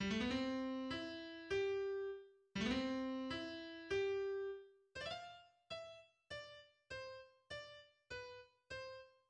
Genre Symphonie
Allegro, à  , en ut majeur, 69 mesures
Introduction de l'Allegro :